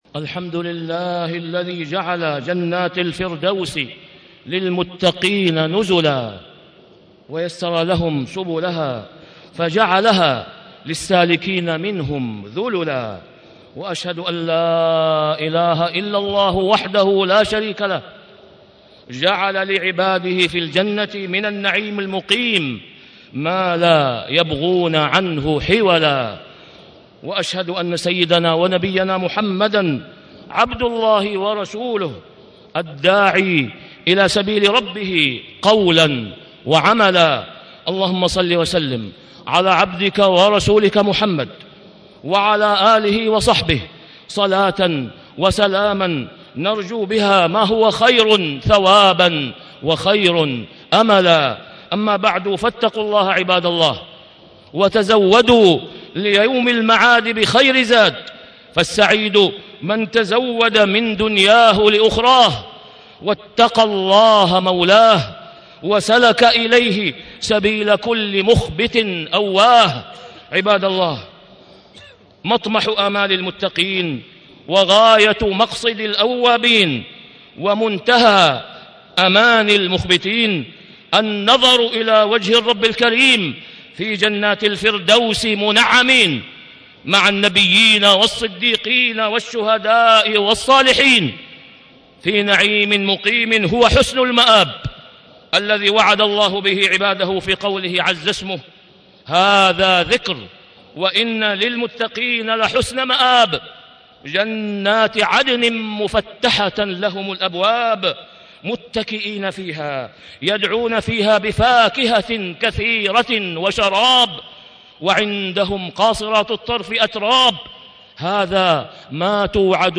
تاريخ النشر ٤ ربيع الأول ١٤٣٦ هـ المكان: المسجد الحرام الشيخ: فضيلة الشيخ د. أسامة بن عبدالله خياط فضيلة الشيخ د. أسامة بن عبدالله خياط أعمال تدخل الجنة